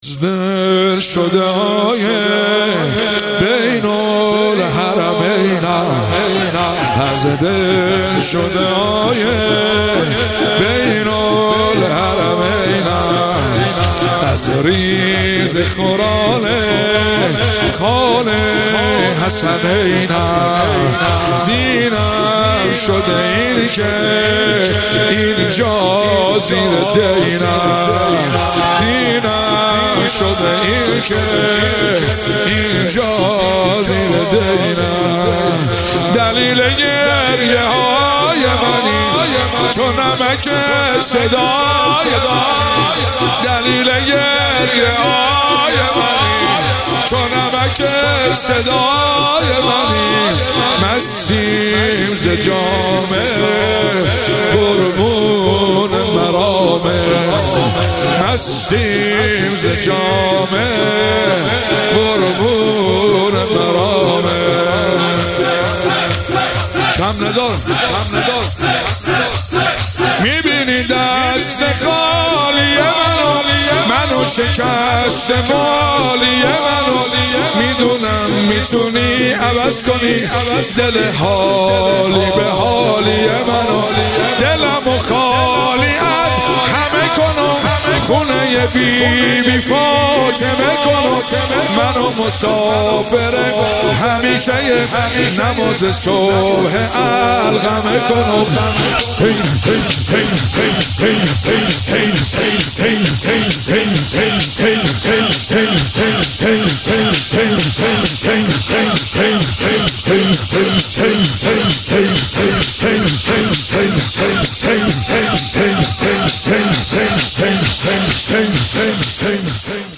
ایام فاطمیه۹۷ هیئت فاطمیون قم